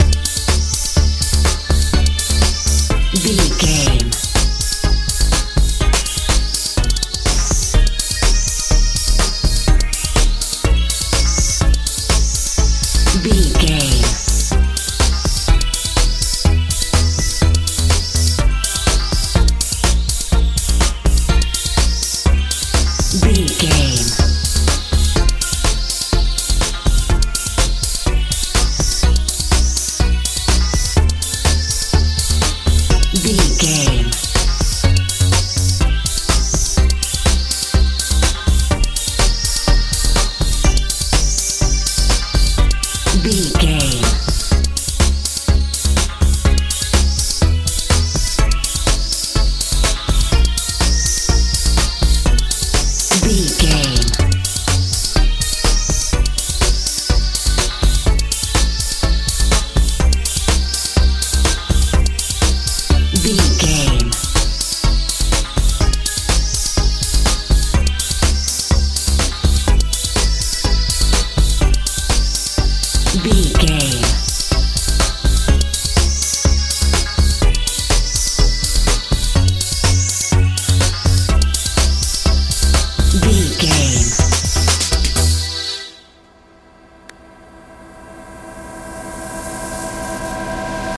euro dance
Ionian/Major
D
mystical
futuristic
synthesiser
bass guitar
drums
uplifting
magical
dreamy